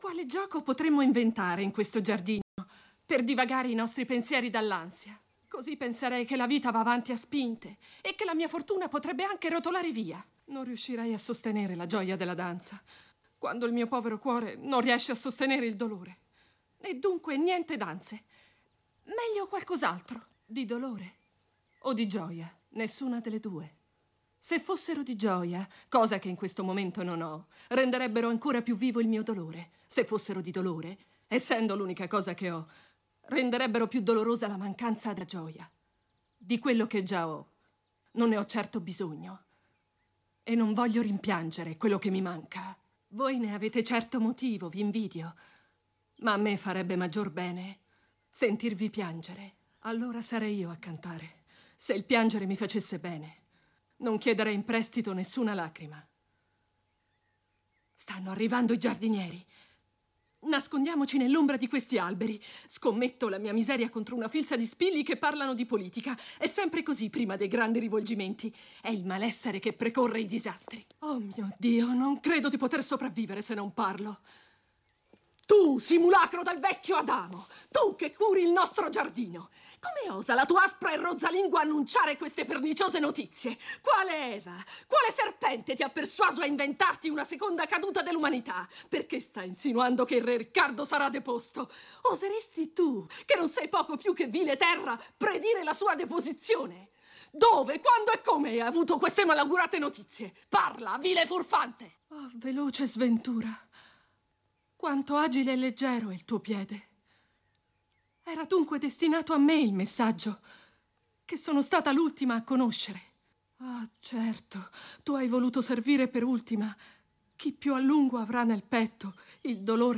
nel ridoppiaggio del film TV